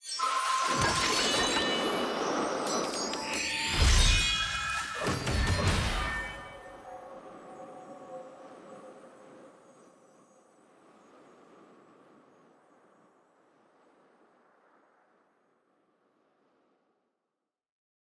sfx-clash-capsule-tier-4-ante-5.ogg